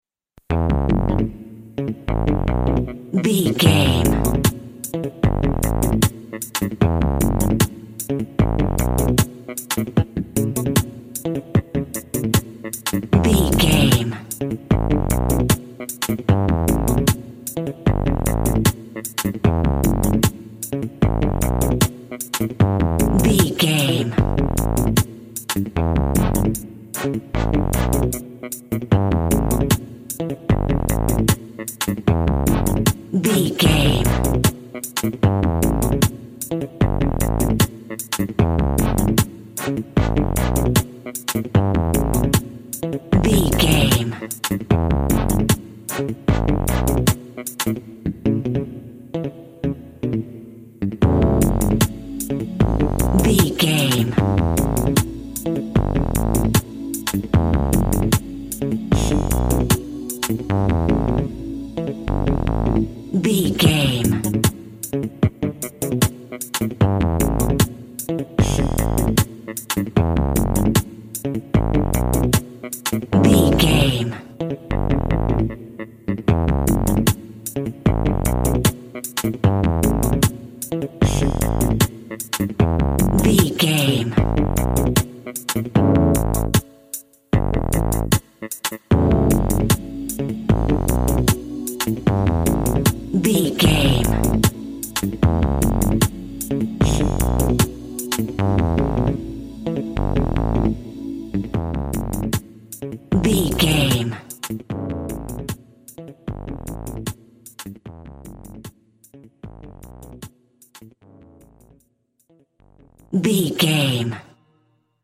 Aeolian/Minor
Slow
tropical
drums
bass
guitar
piano
brass
pan pipes
steel drum